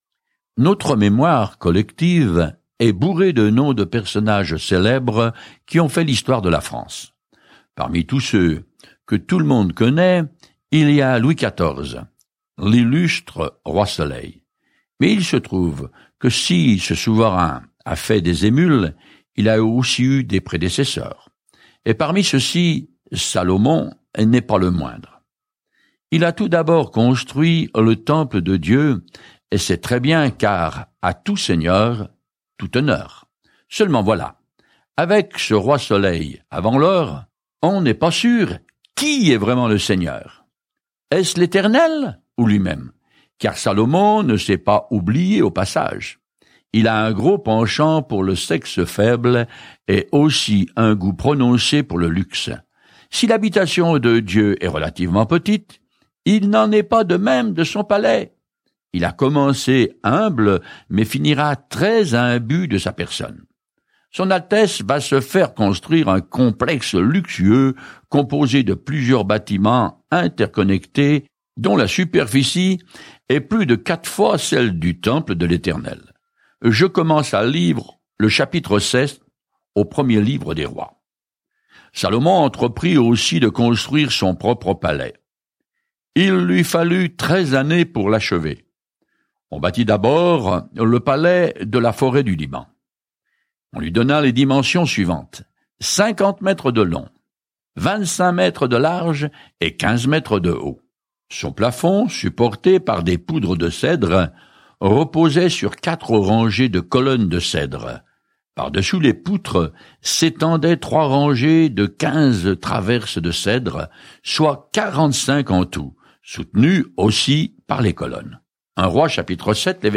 Écritures 1 Rois 6:11-38 1 Rois 7 1 Rois 8:1-63 Jour 4 Commencer ce plan Jour 6 À propos de ce plan Le livre des Rois continue l’histoire de la façon dont le royaume d’Israël a prospéré sous David et Salomon, mais s’est finalement dispersé. Parcourez quotidiennement 1 Rois en écoutant l’étude audio et en lisant certains versets de la parole de Dieu.